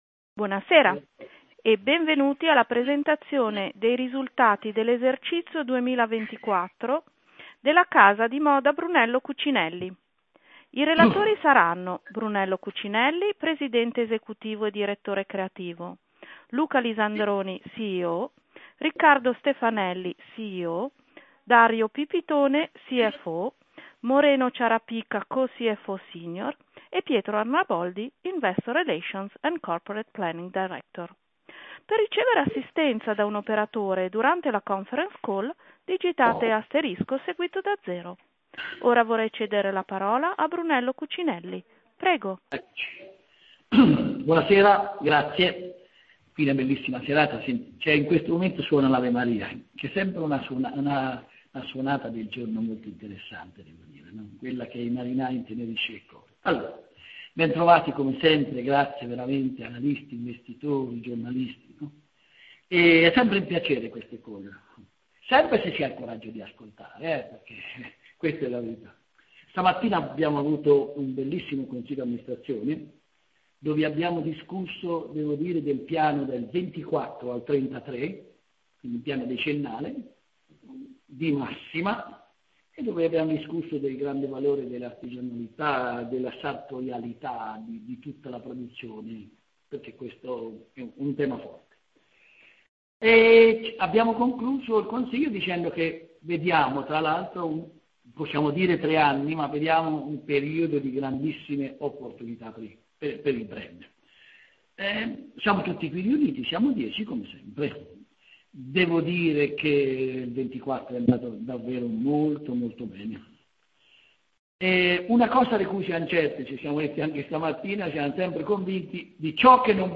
Conference_call_risultati_FY_2024.mp3